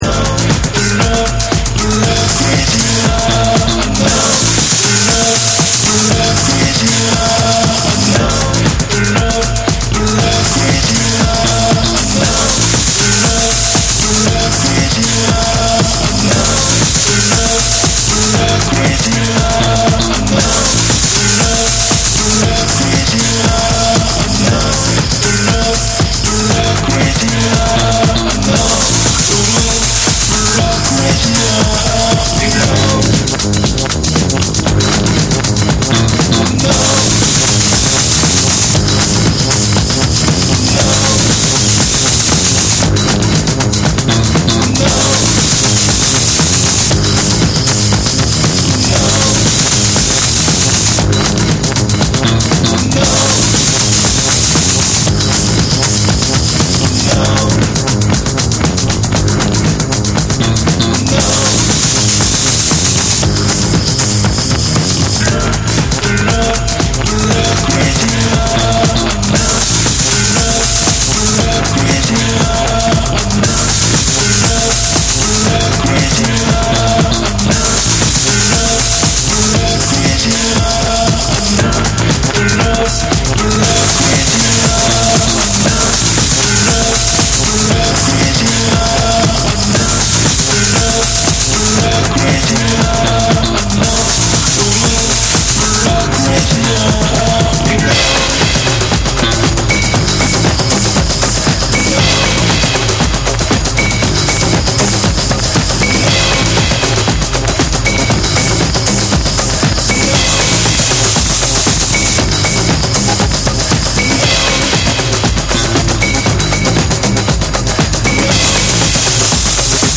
日本のヘヴィー・ロック・バンド
JAPANESE / TECHNO & HOUSE